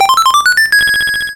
RedCoin5.wav